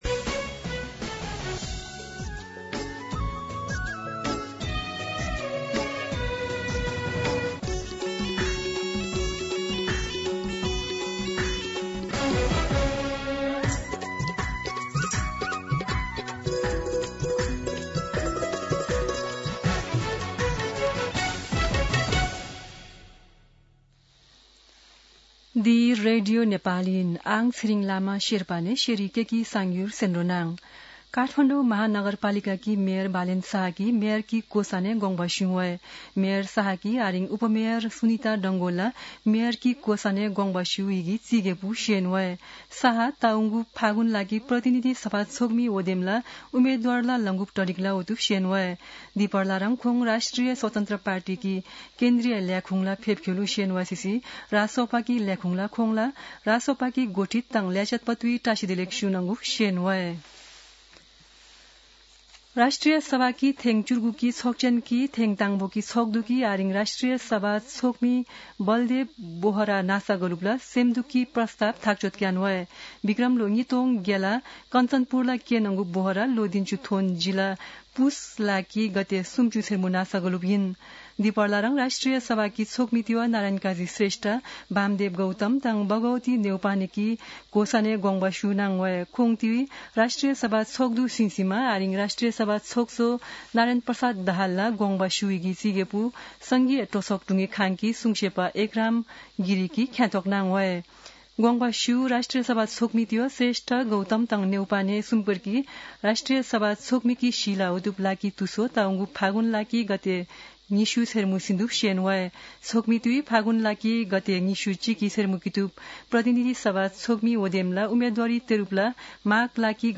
शेर्पा भाषाको समाचार : ४ माघ , २०८२
Sherpa-News-04.mp3